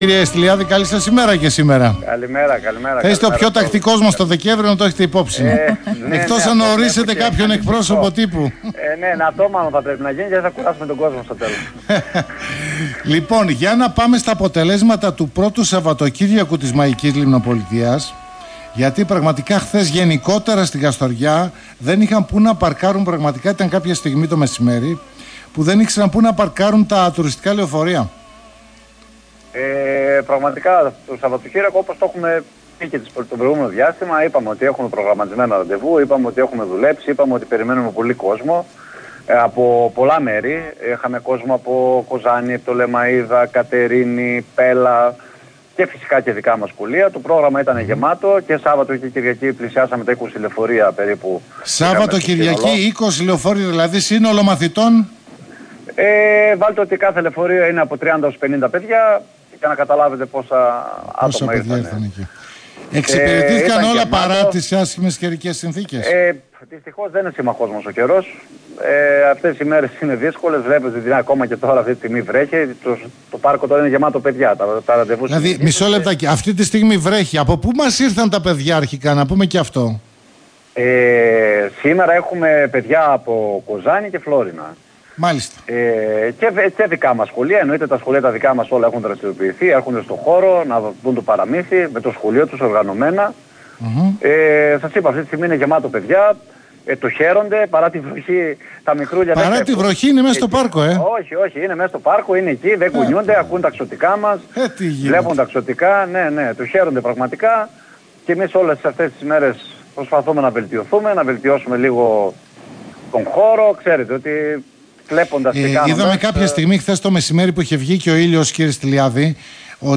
Μεγάλη η επισκεψιμότητα, παρά τη βροχή, τις πρώτες ημέρες του θεματικού πάρκου του Πινόκιο – Συνέντευξη